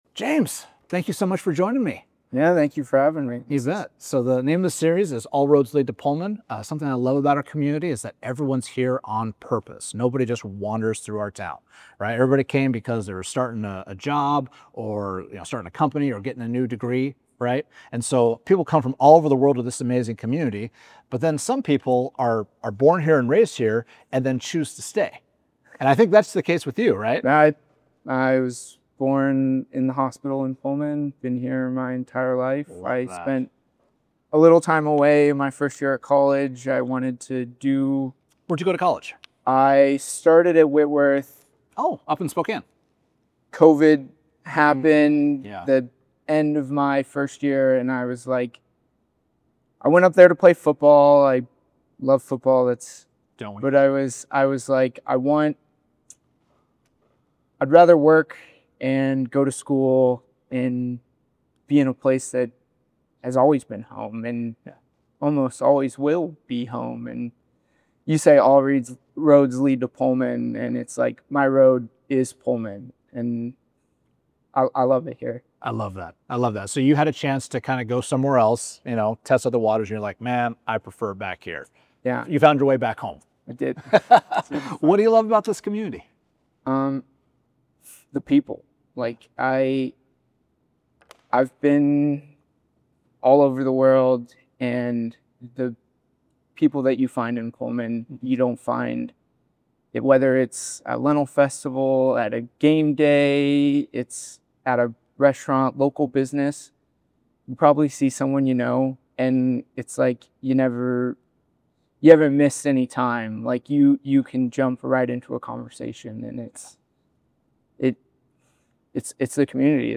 I sat down with him to hear his story of growing up on the Palouse, choosing to stay after college, and the role of local government in building community.